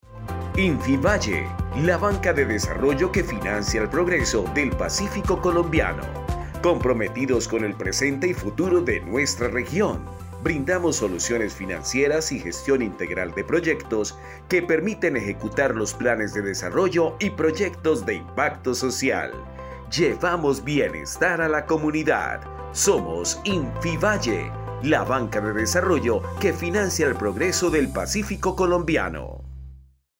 Cuña Pacifico Col.mp3